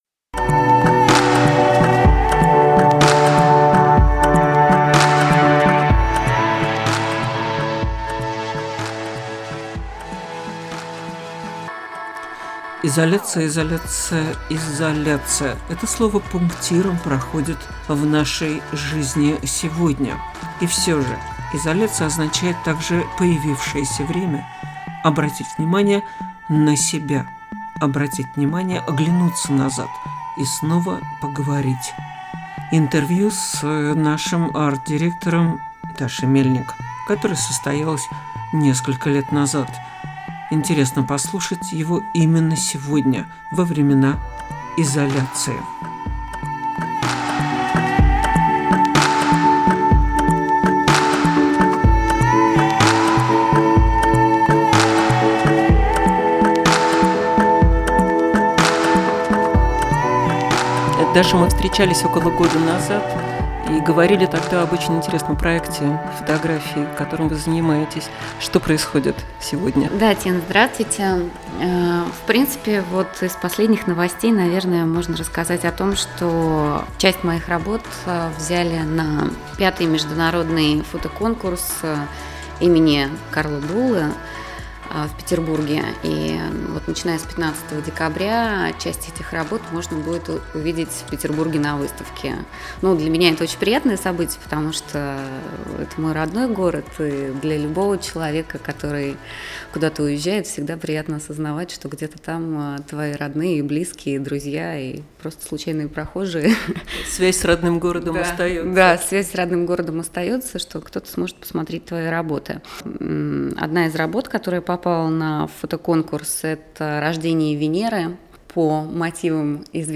CAUSE TO CONVERSE Interview Series